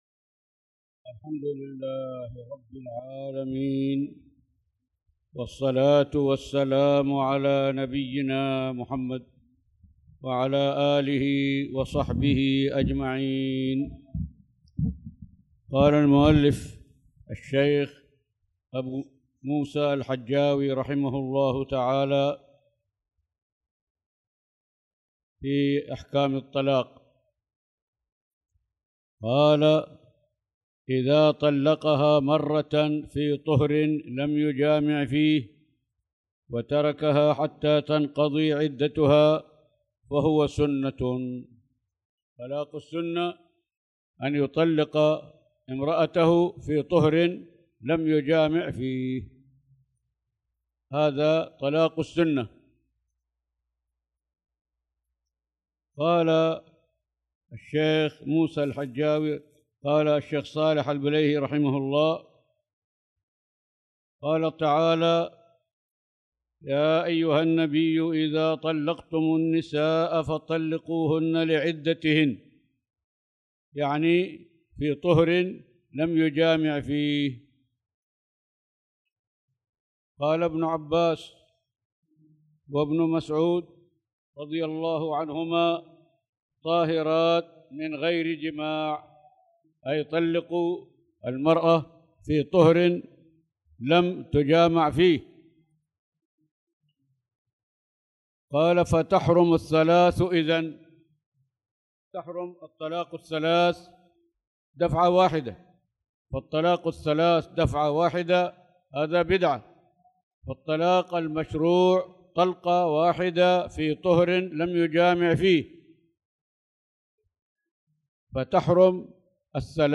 تاريخ النشر ١٣ ذو القعدة ١٤٣٧ هـ المكان: المسجد الحرام الشيخ